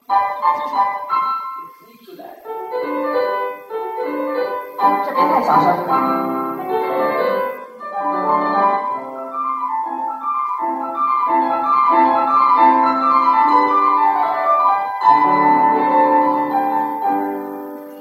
[44-46]现在很重要，就象把一个球扔下来让它开始弹，我一开始做一个小小的渐快，最后一组慢下来，然后走到旋律去，试试看你能不能这么做：